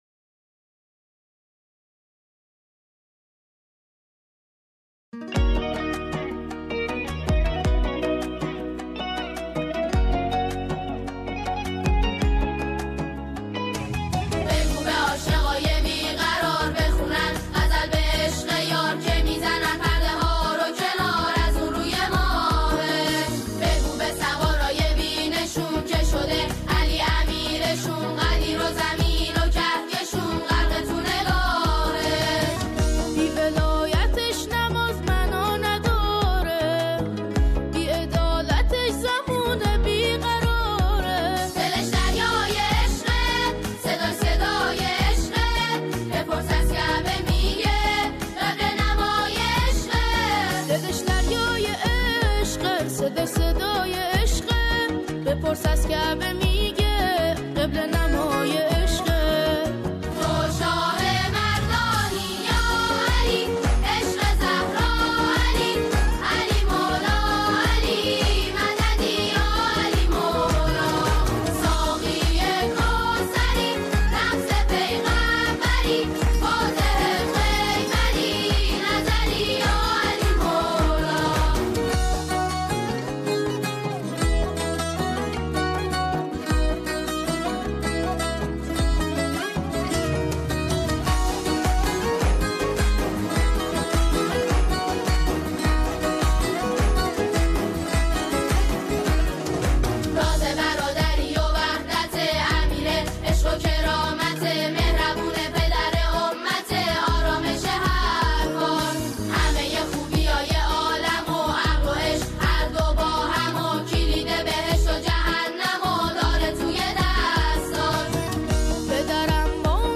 همخوانی